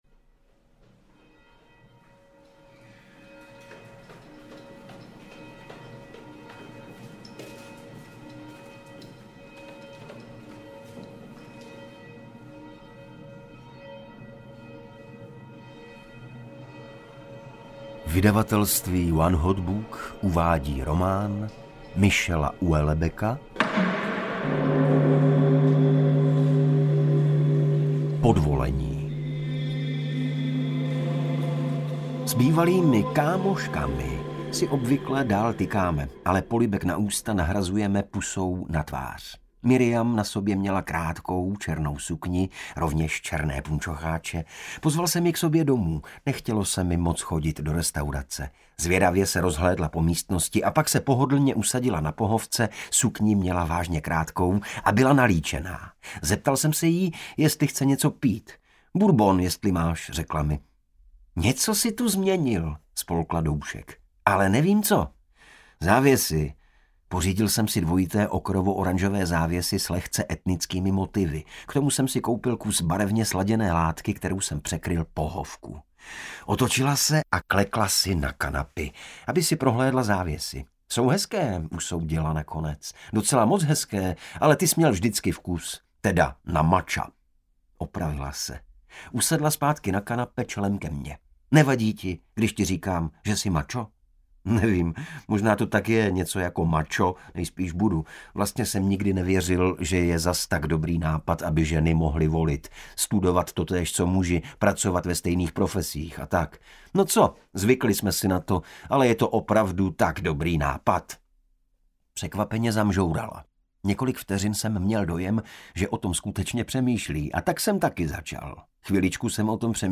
Podvolení audiokniha
Ukázka z knihy
• InterpretIgor Bareš